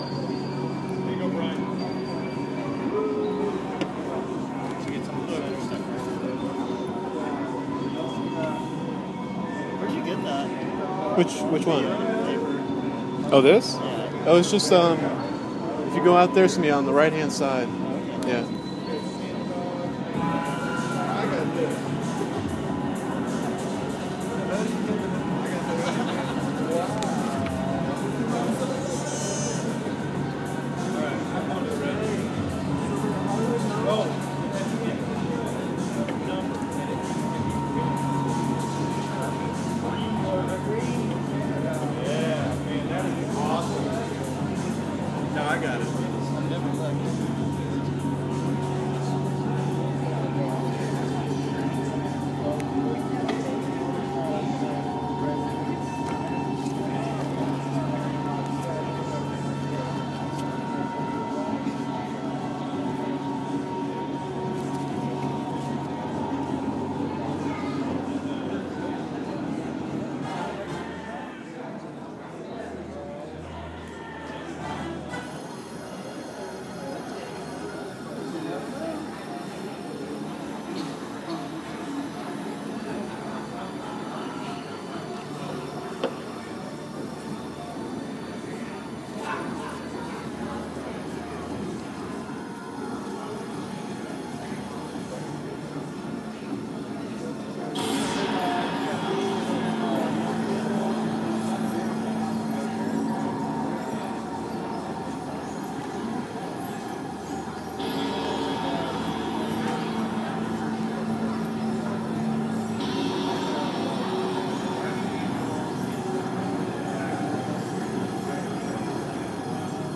Simultaneous Presence – EFA Center, June 6
I love background noise.
But on the other hand I don’t want to do so, for there is a certain beauty in the cacophony, each sound lending and assigning meaning to the other. The noise is a dialogue on human life, one that is constantly in flux and conveying something more akin to truth than anything we could purposefully create.